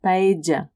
paella / paedja/